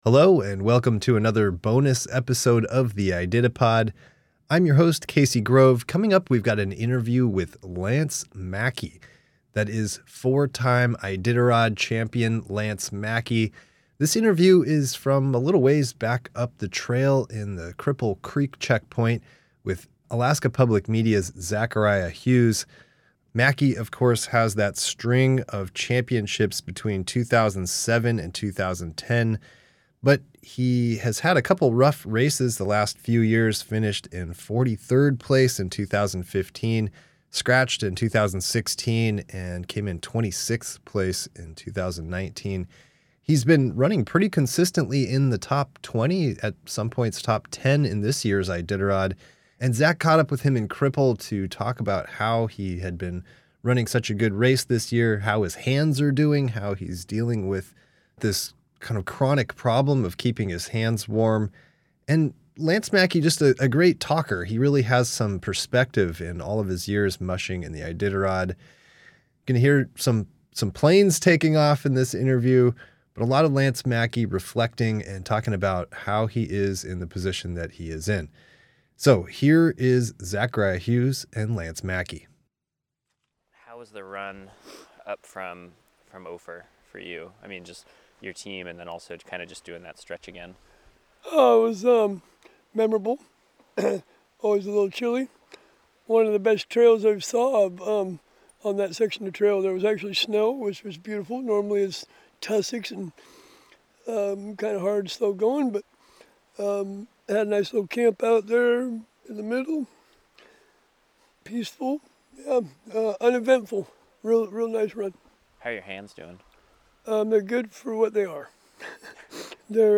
Cripple Creek interview with Lance Mackey